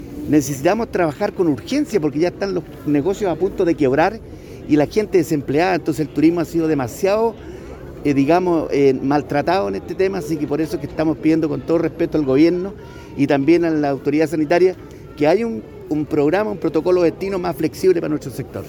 A la molestia se sumó el alcalde de Pucón, Carlos Barra.